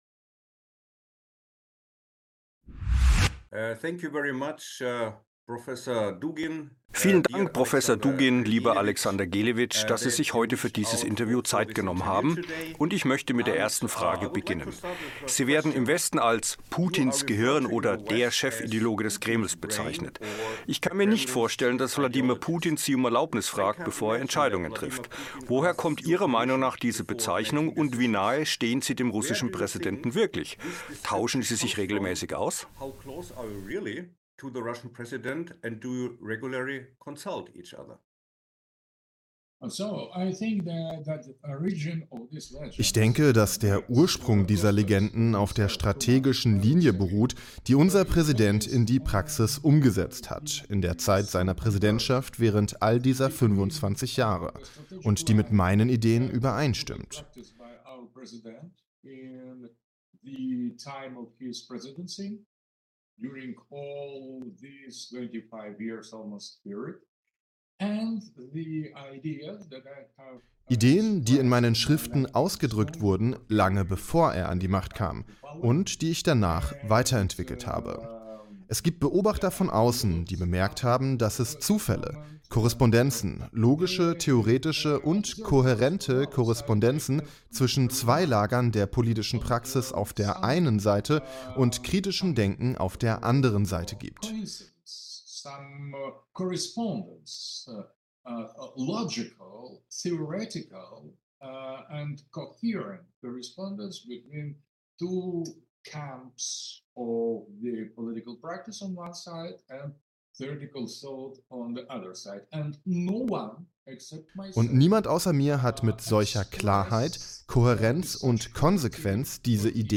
Interview: Alexander Dugin - Chefideologe des Kreml